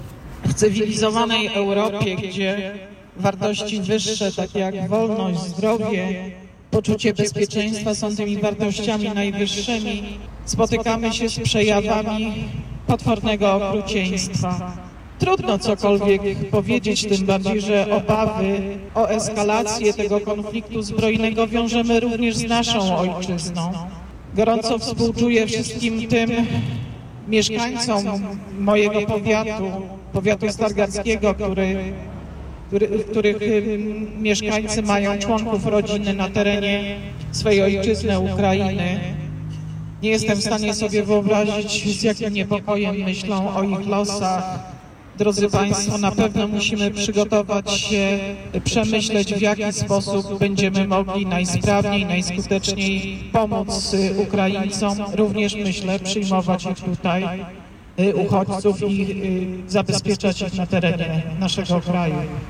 Dziś na Placu Wolności miejsce miała spontaniczna demonstracja, organizowana przez Uliczną Opozycję.
Mówi starosta Iwona Wiśniewska.